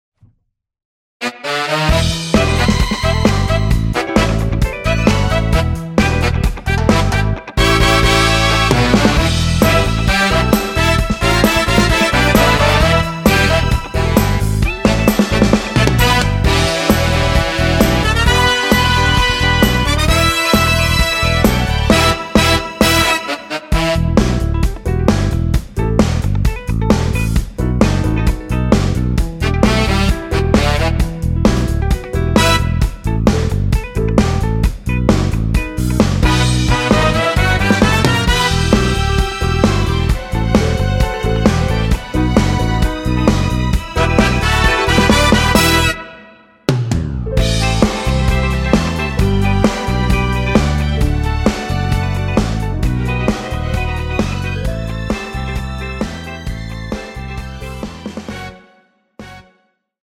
원키에서(+7)올린 여성분이 부르실수 있는 키의 MR입니다.
Em
앞부분30초, 뒷부분30초씩 편집해서 올려 드리고 있습니다.